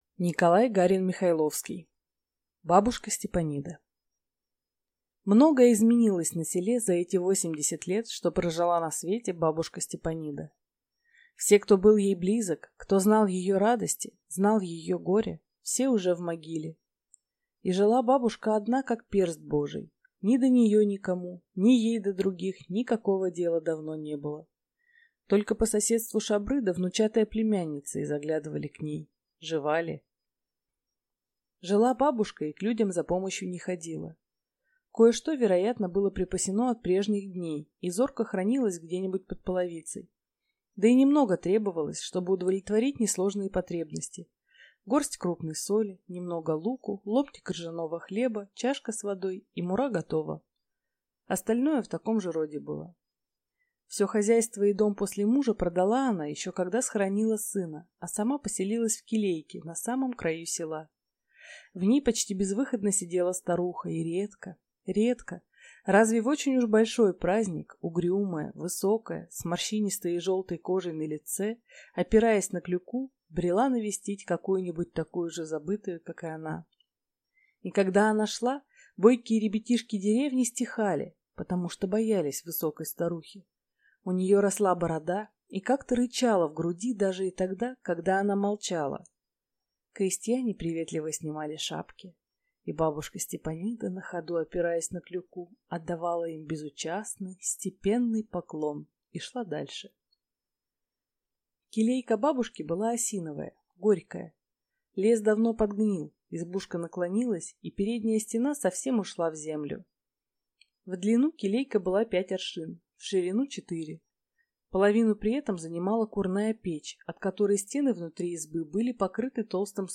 Аудиокнига Бабушка Степанида | Библиотека аудиокниг